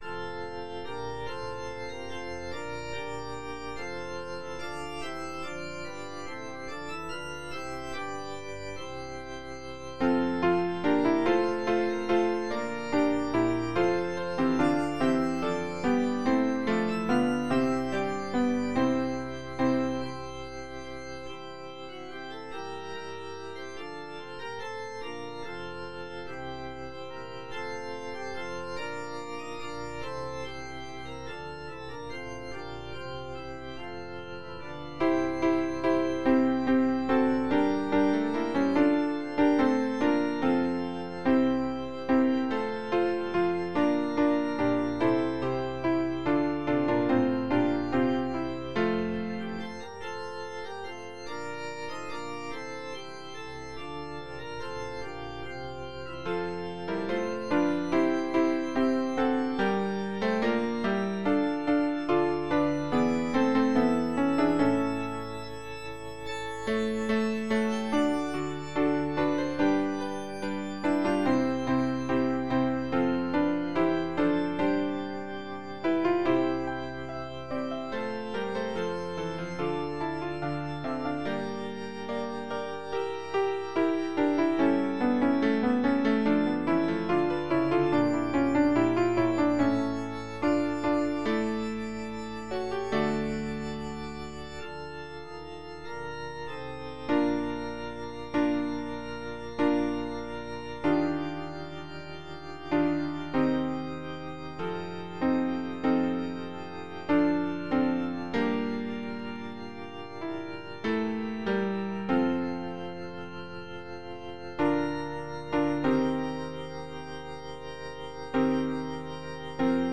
- On ne peut régler la balance entre la «voix en exergue» à droite, et les autres à gauche, qu'en utilisant les réglages de l’ordinateur.